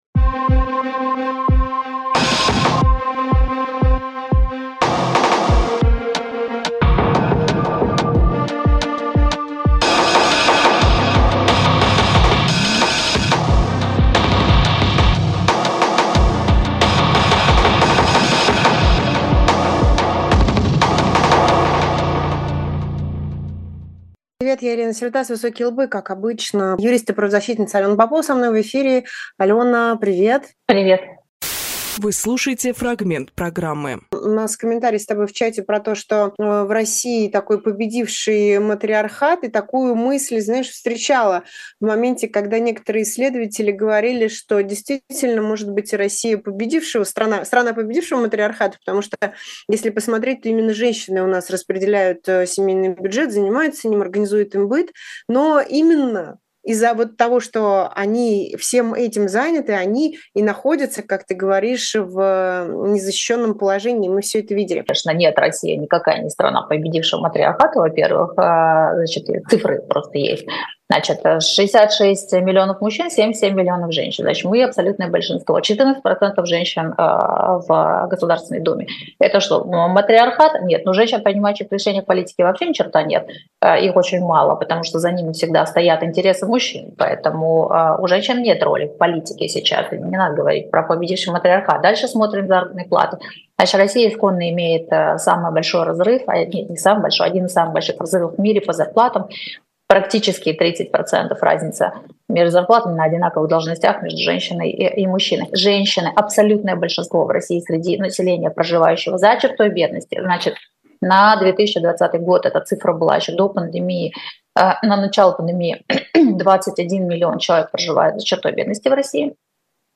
Фрагмент эфира от 09.03.24